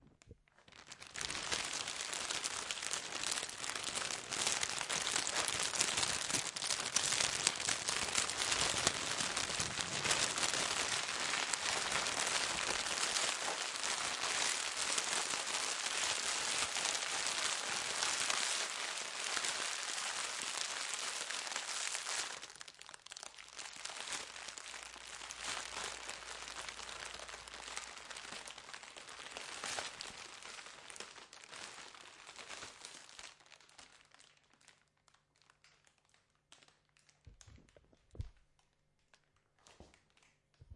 一个小火堆在燃烧
描述：小火堆在燃烧的声响。 在那里有一些额外的噪音（一些风声，遥远的车辆，轻脚步，钥匙，照相机噪音和一些鸟），我只提供了完整的文件，所以你可以编辑和使用，选择最适合你的部分。
标签： 爆裂 噼里啪啦
声道立体声